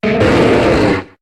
Cri de Terrakium dans Pokémon HOME.